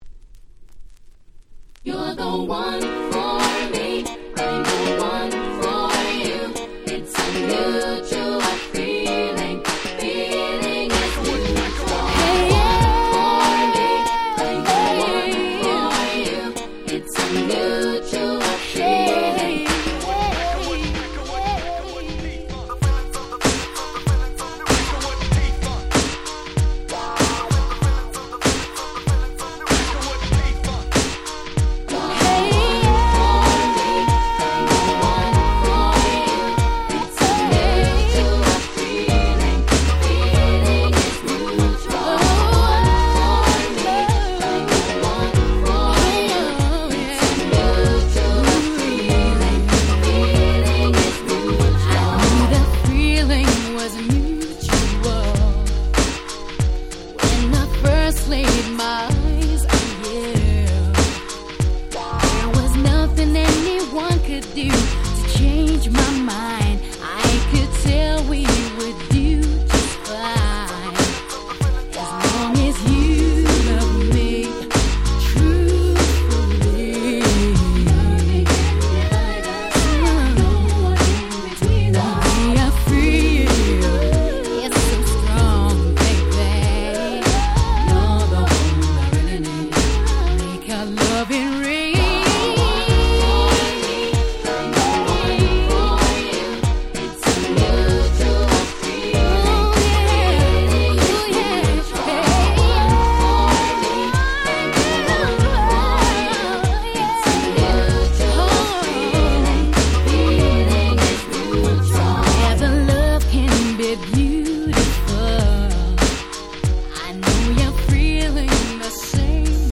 96’ Super Nice UK Street Soul / R&B Compilation !!